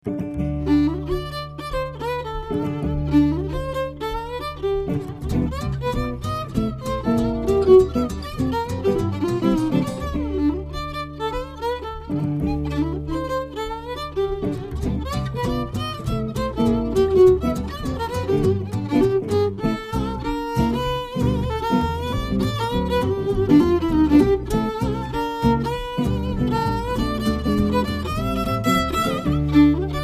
violino
chitarra semiacustica
chitarra acustica
contrabbasso
atmosfere festose e swinganti